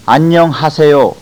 Ahn Young Hah Se Yo- How are you?
ahn_young_hah_seh_yo.au